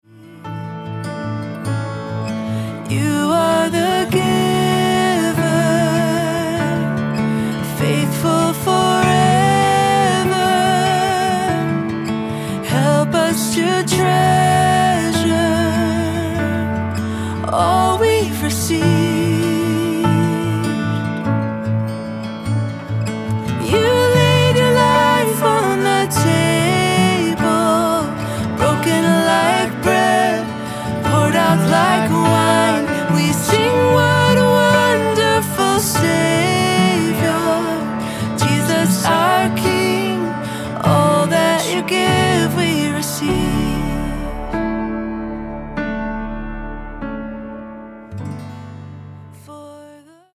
Guitar Chart - Recorded Key (D)